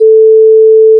A4.aiff